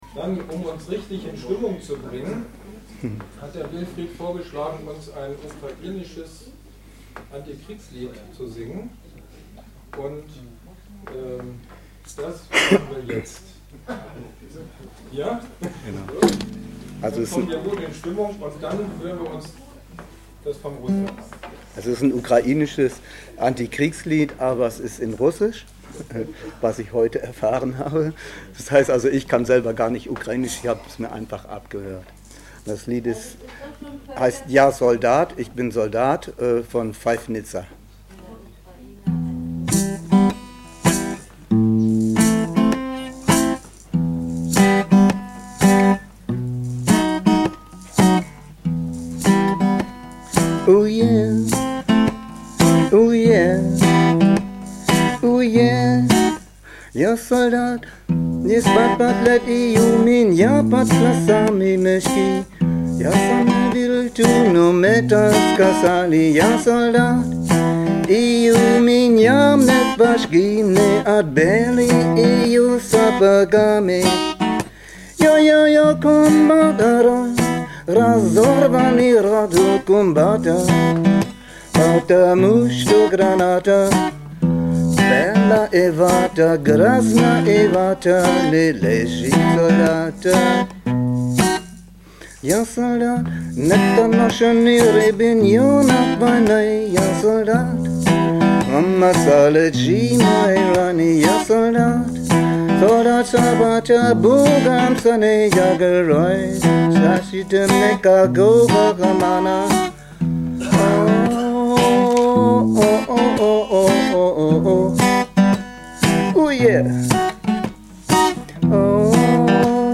Zum Auftakt: Lied